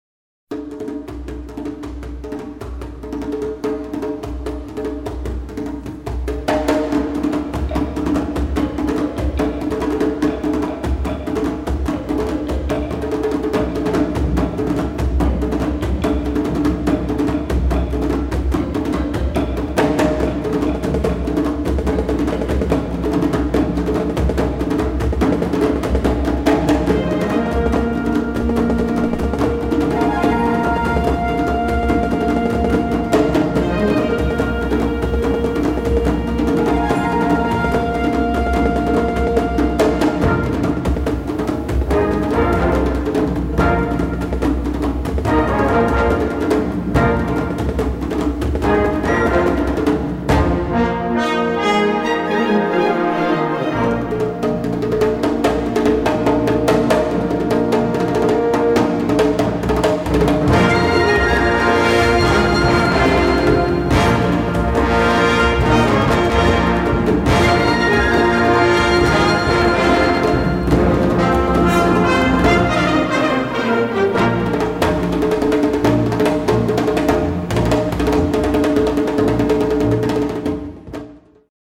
Gattung: Savannah-Impression
Besetzung: Blasorchester
Das Lied eines exotischen Vogels erklingt...